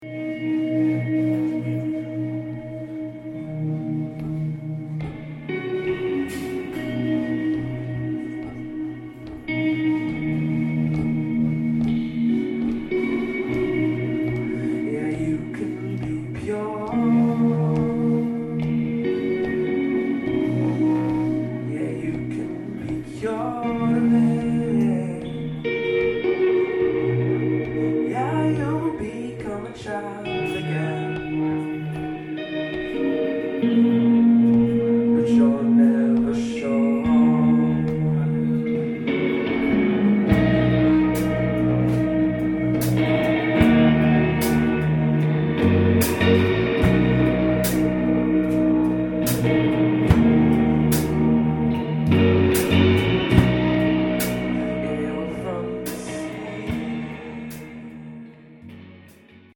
Live at the Revolution Cafe